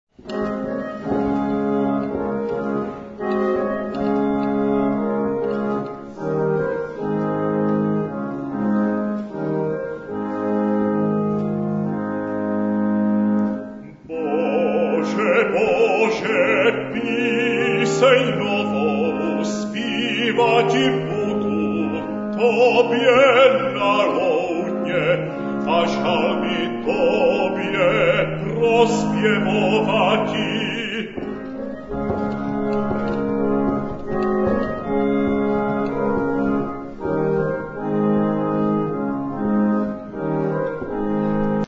Zde jsou uvedeny ukázky skladeb, které zazněli na koncertě.